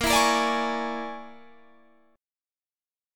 A#7#9b5 chord